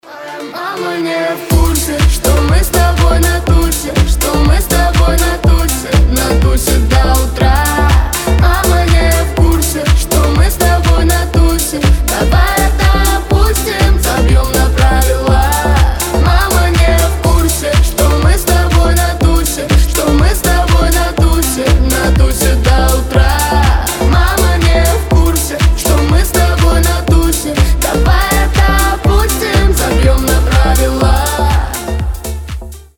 • Качество: 320, Stereo
поп
дуэт